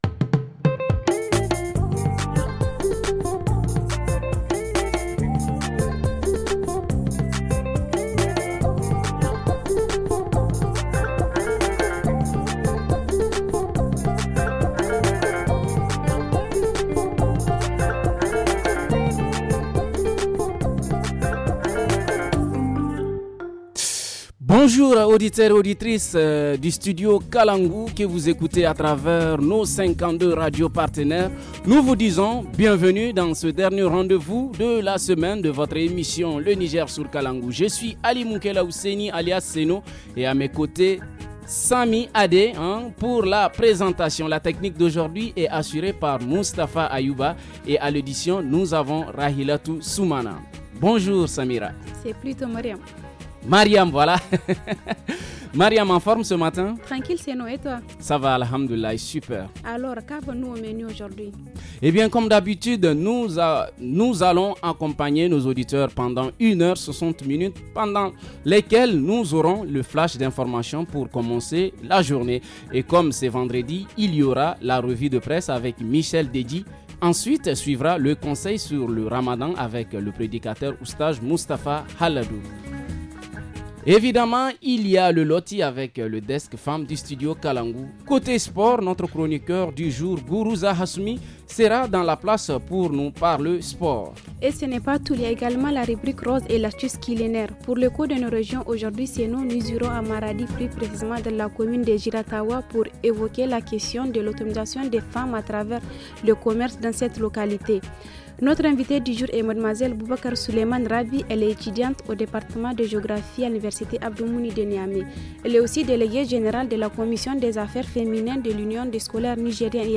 – Reportage à Giratawa région parle du commerce des femmes pour leur autonomisation ;